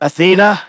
synthetic-wakewords
ovos-tts-plugin-deepponies_Michael_en.wav